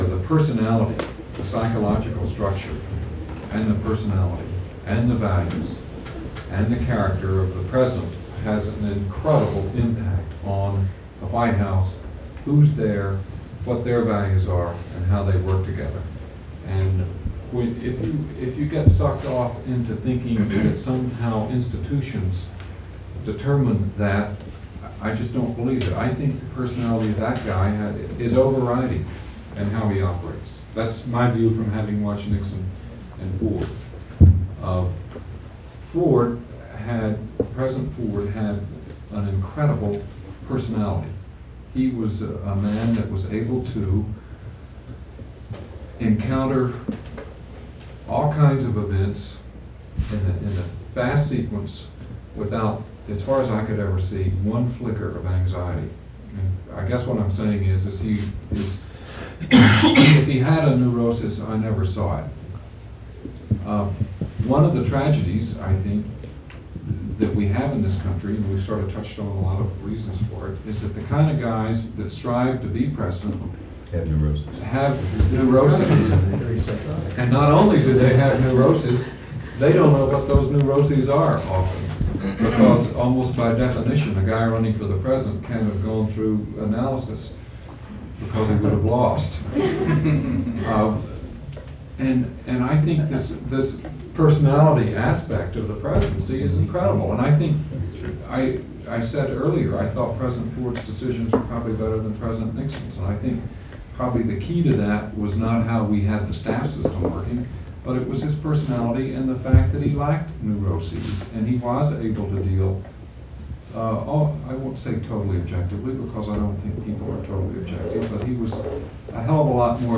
'If He Had a Neurosis, I Never Saw It' Photo: National Archives and Records Administration Jerry H. Jones, deputy assistant to President Gerald Ford, spoke about Ford’s “incredible personality” and his ability to handle fast-moving and competing events. Ford came into the Oval Office abruptly and had less time to “learn the presidency.”
Date: April 23, 1977 Participants Jerry H. Jones Associated Resources Session Three: Effective Coordination at the Presidential Level The Gerald Ford Presidential Oral History Audio File Transcript